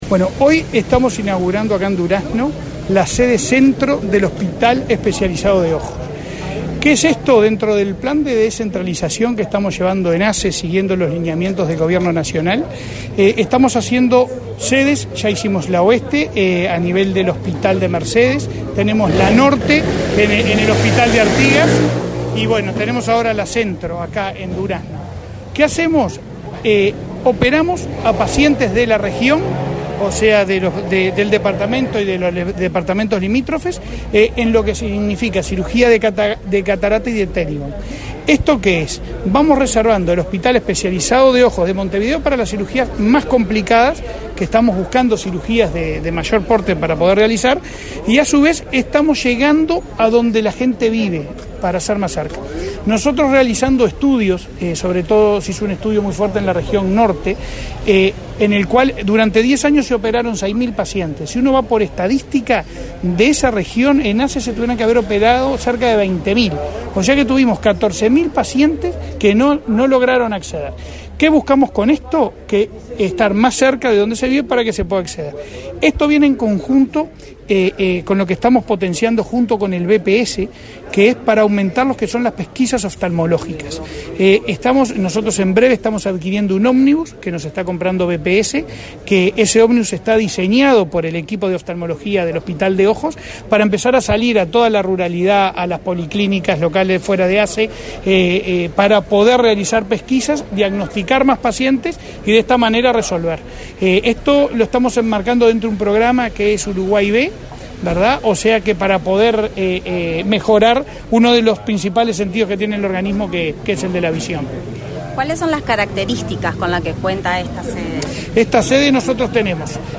Declaraciones del presidente de ASSE, Leonardo Cipriani
Declaraciones del presidente de ASSE, Leonardo Cipriani 17/03/2022 Compartir Facebook X Copiar enlace WhatsApp LinkedIn Tras participar en la inauguración de la sede centro del Hospital Especializado de Ojos, en Durazno, este 17 de marzo, el presidente de la Administración de los Servicios de Salud del Estado (ASSE), Leonardo Cipriani, efectuó declaraciones a Comunicación Presidencial.